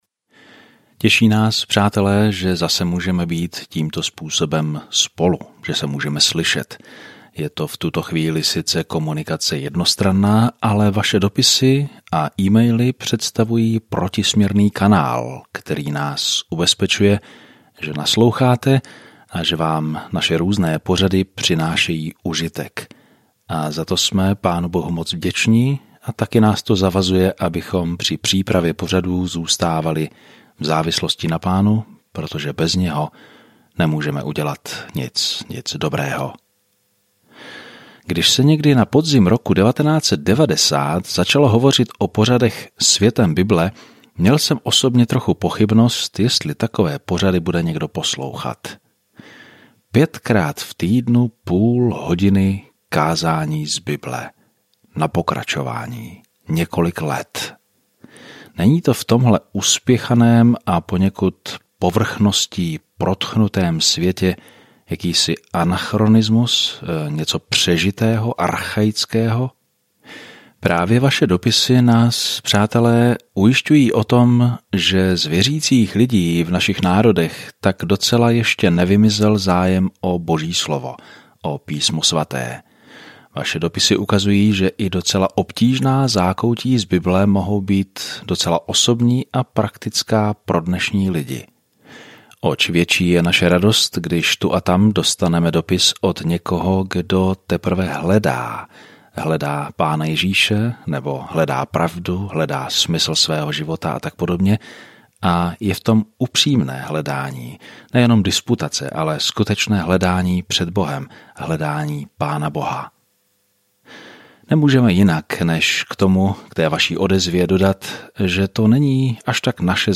Denně procházejte Hebrejcům, když posloucháte audiostudii a čtete vybrané verše z Božího slova.